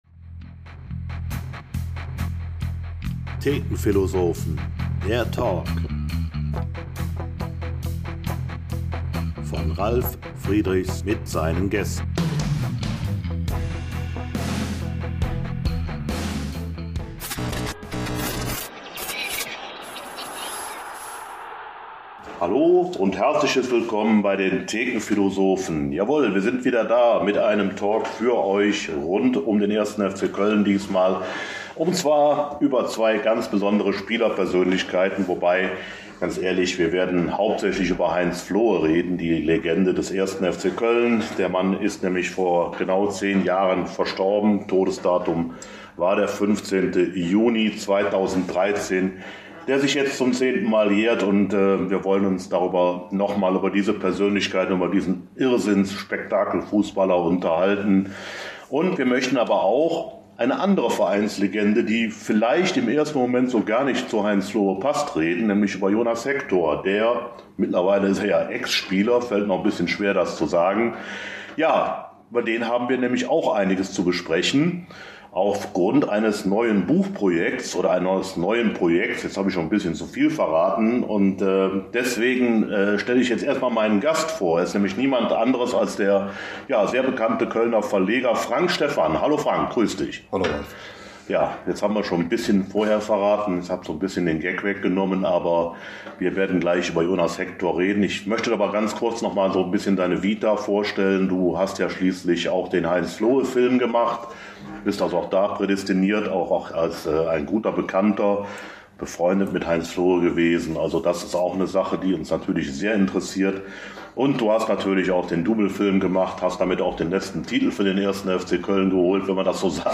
Dabei werden aber immer wieder Schnittmengen und gar Parallelen zum FC-Hero der Neuzeit – eben Hector – entdeckt und im Dialog besprochen. 84 spannende Minuten über die FC-Legenden, aber auch über den Verein an sich.